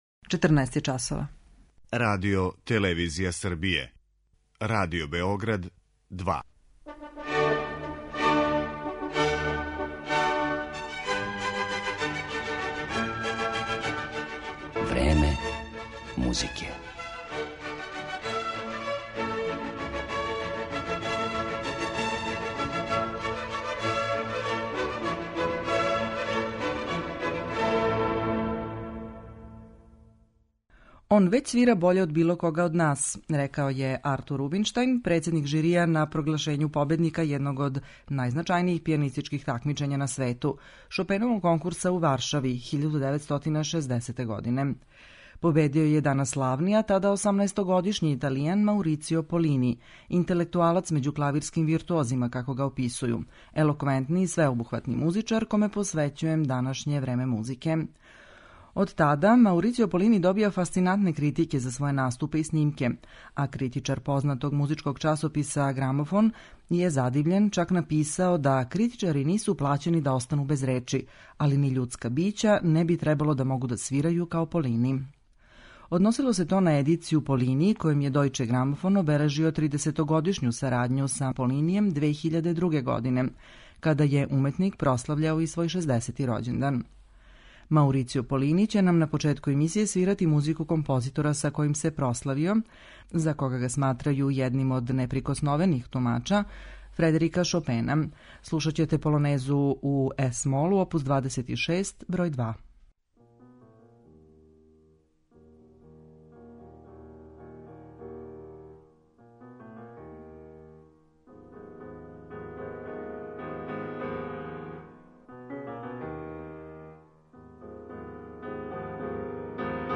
Овај славни италијански солиста ће изводити композиције Фредерика Шопена, Јоханеса Брамса, Игора Стравинског, Лудвига ван Бетовена и Роберта Шумана.